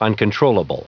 Prononciation du mot uncontrollable en anglais (fichier audio)
Prononciation du mot : uncontrollable